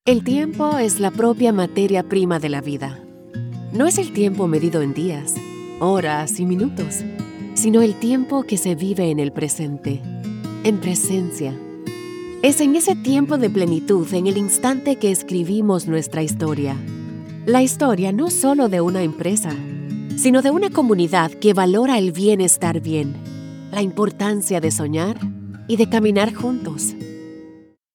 Tiempo de Relación – Locução em espanhol para vídeo corporativo
Natura_Tempo_de_Relacao_com_Trlha.mp3